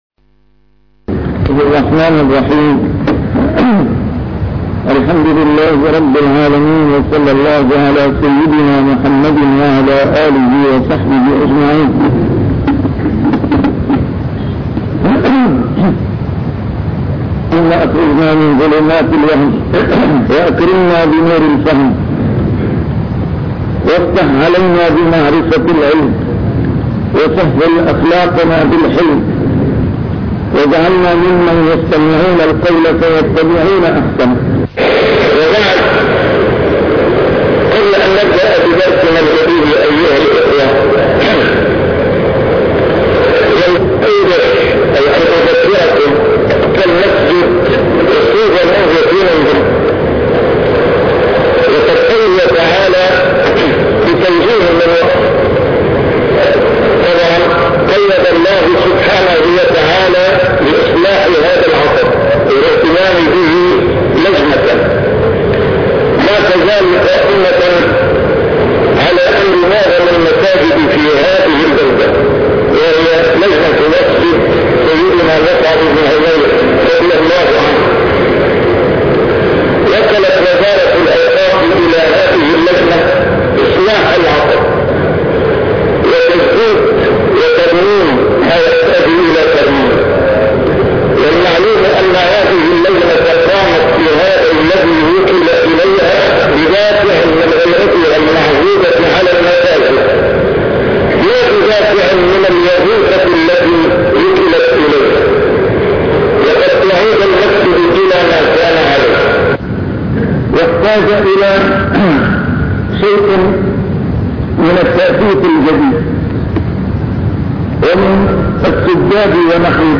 72- شرح رياض الصالحين: التقوى (أول درس في جامع تنكز)